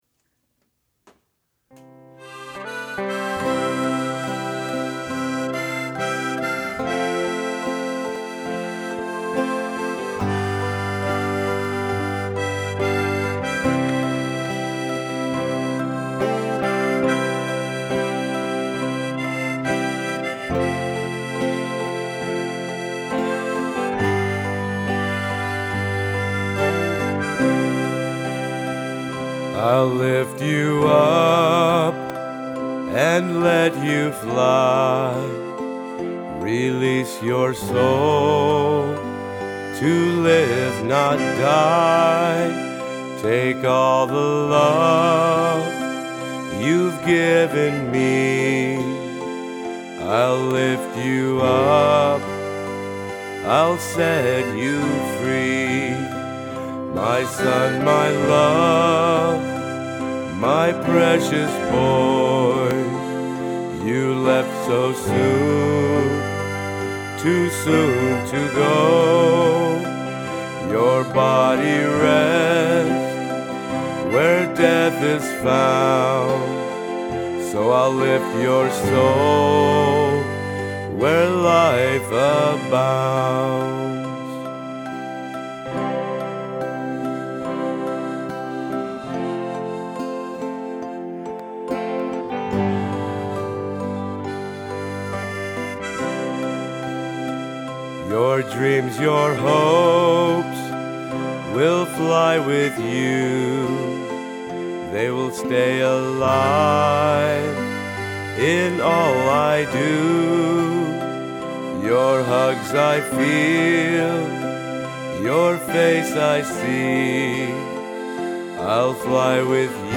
A lullaby